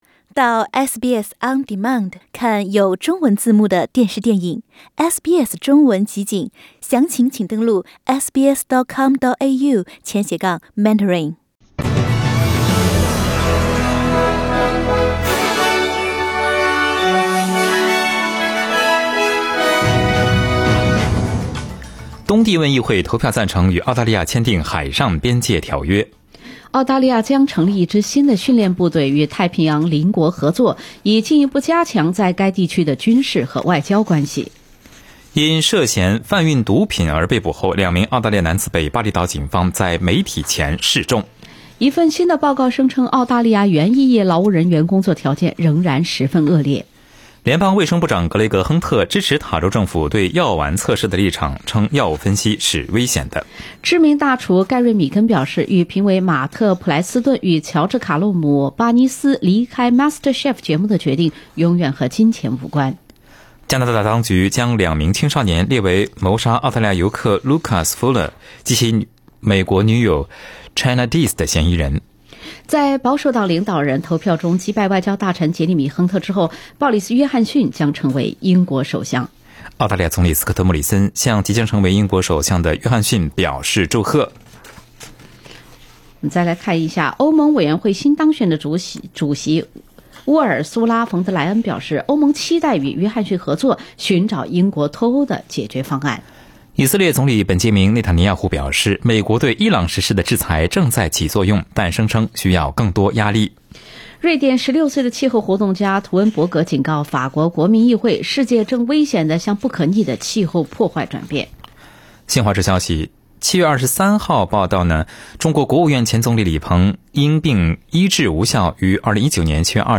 SBS早新闻（7月24日）